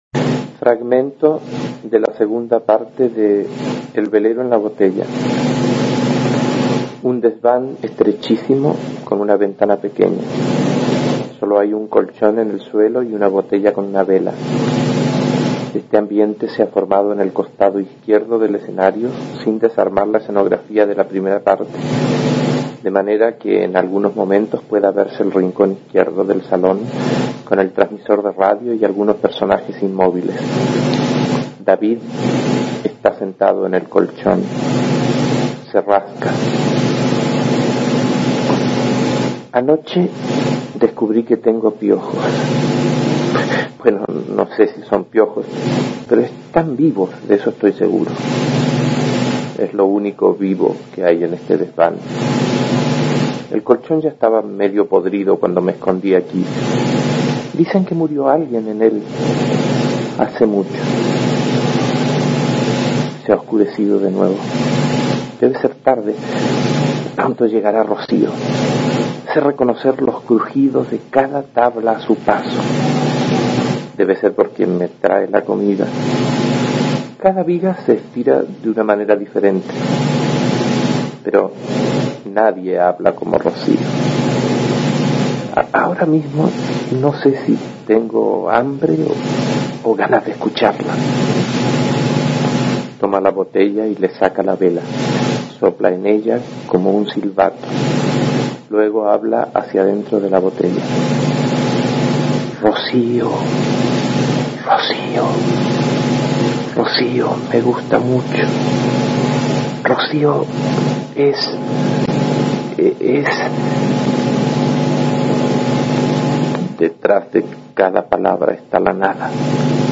Aquí se puede escuchar al dramaturgo chileno Jorge Díaz leyendo un fragmento de la segunda parte de El velero en la botella (1962).